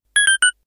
item_drop.wav